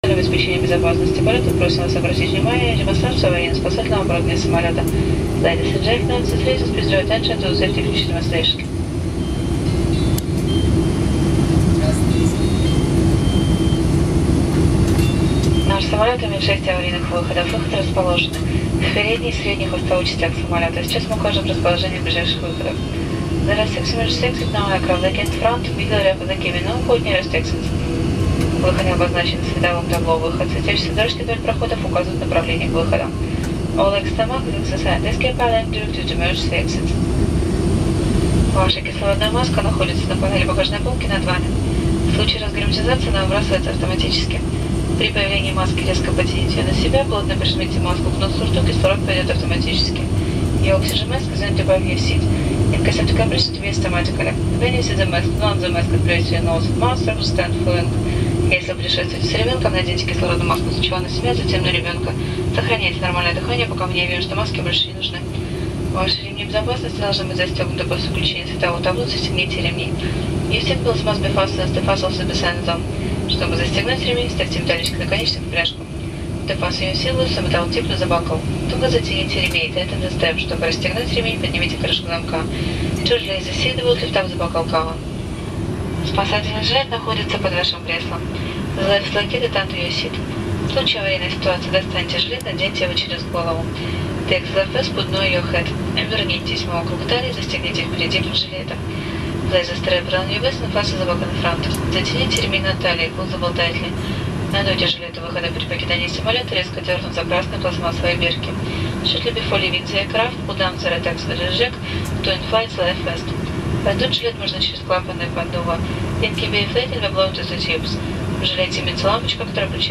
Звуковое сопровождение инструктажа перед полетом на самолете с демонстрацией действий стюардессы в экстренных ситуациях